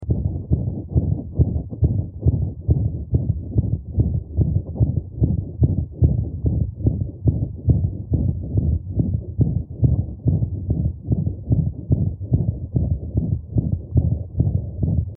Good quality headphones or loudspeakers (capable to transmit all frequency components) are necessary to hear the sounds at best quality.
Heart sounds and digital phonocardiogram (dPCG) of a 4-month old Basset Hound with moderate pulmonic stenosis (PS) producing a grade 3/6 holosystolic crescendo-decrescendo murmur (between S1 and S2).
Mild pulmonic insufficiency (PI) was also present but no diastolic murmur could be distinguished during auscultation of this dog.
This murmur was recorded at the point of its maximal intensity over the pulmonic valve area (left heart base).